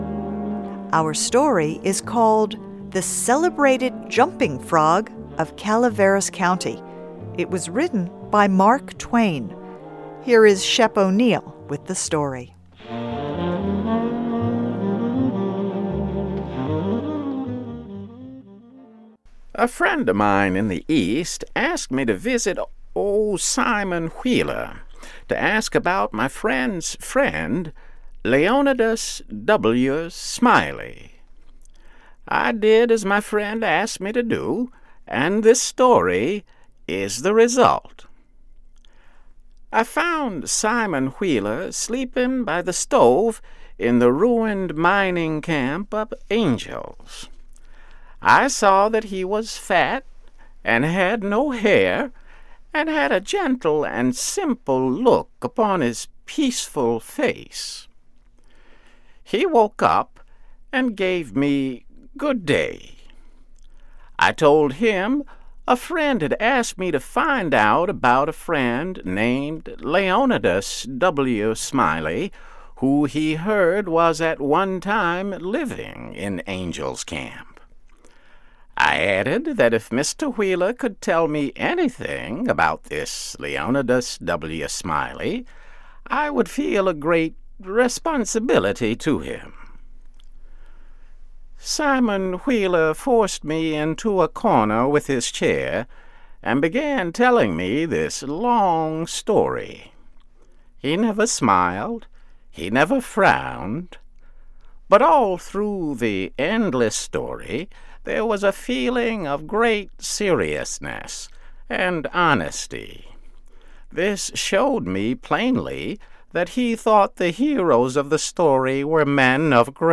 2016 Editor's note: This story includes some of the informal dialect that Mark Twain liked to include in his writing.